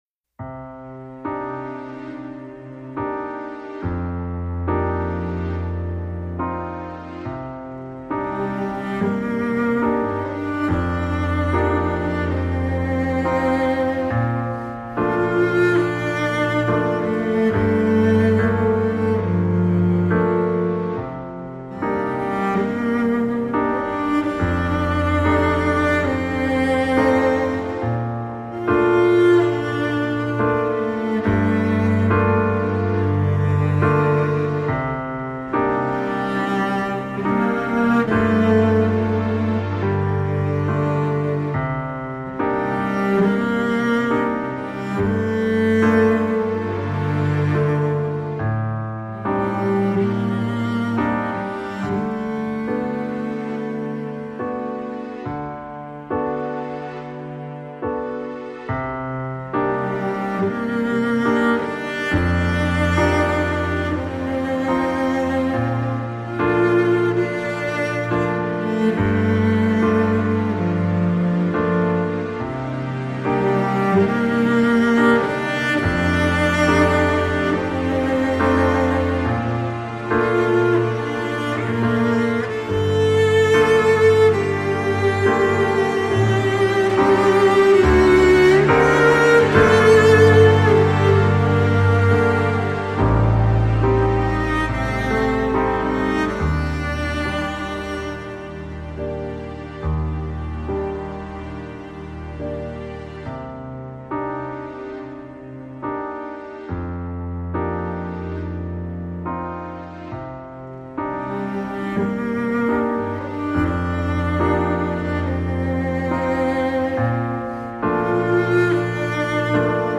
器乐演奏家系列
他的旋律　抚慰了城市边缘每个失眠的灵魂